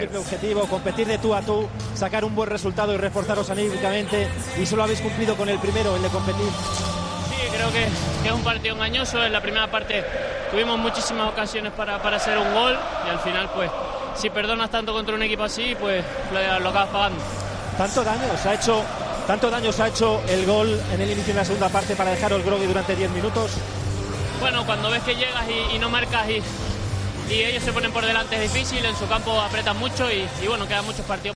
El futbolista del Barça ha valorado en el micrófono de Movistar la derrota de su equipo en Múnich.